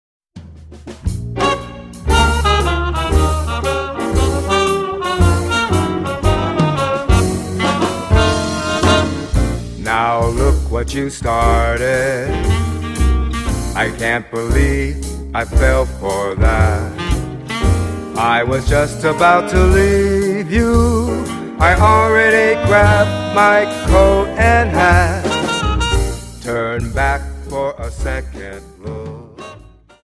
Dance: Slowfox Song